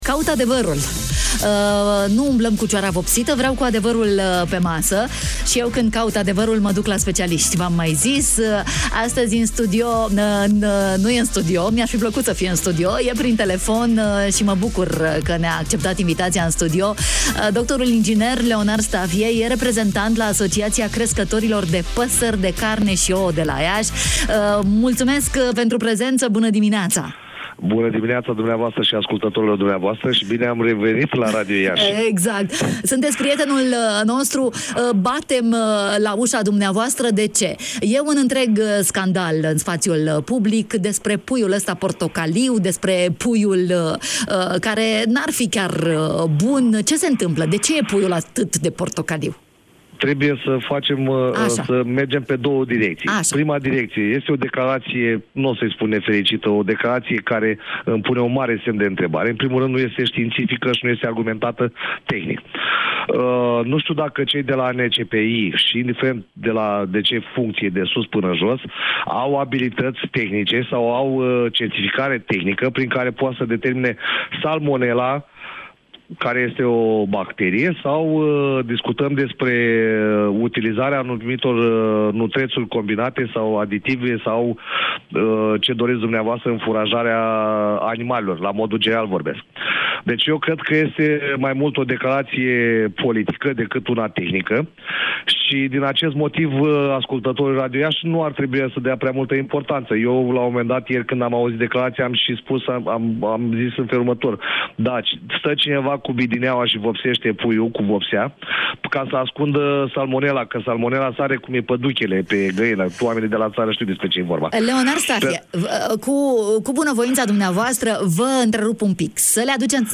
în direct la Radio Iași
Dacă e galben e mai sănătos? De ce e mai scump? Răspunsul la aceste întrebări l-am căutat astăzi în matinalul de la Radio România Iași.